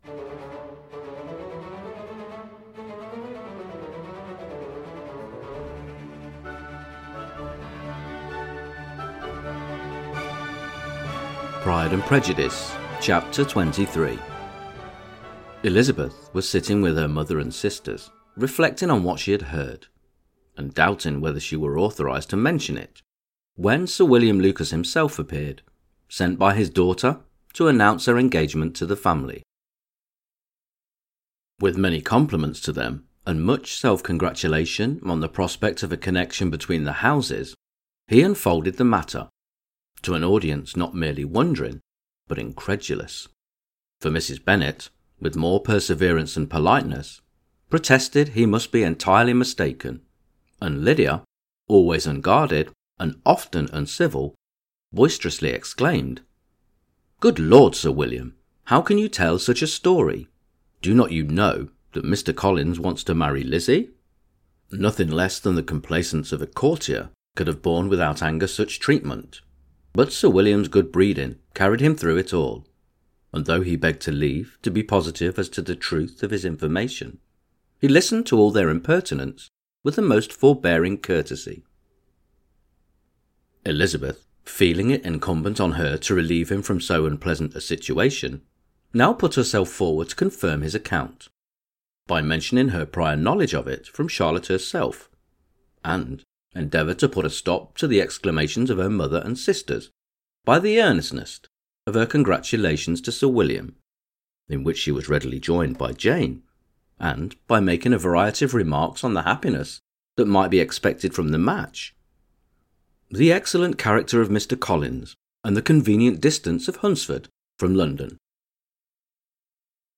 Pride and Prejudice – Jane Austen Chapter 23 Narrated